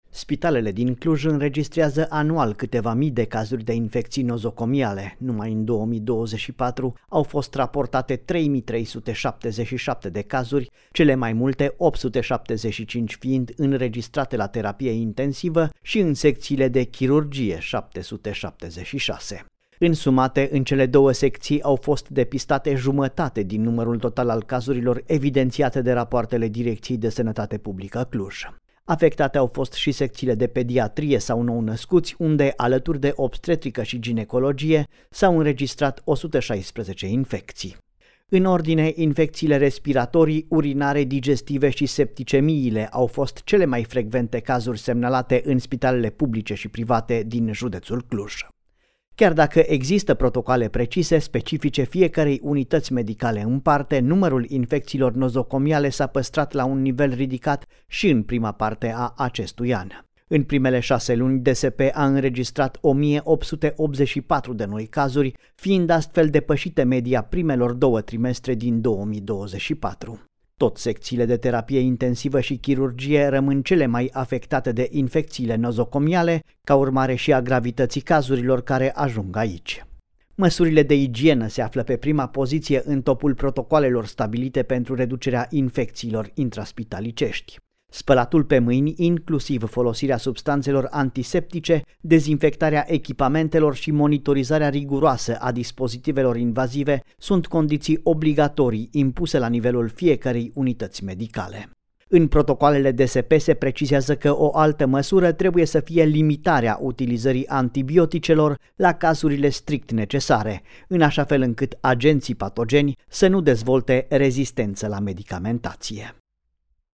relatare-infectii-nozocomiale.mp3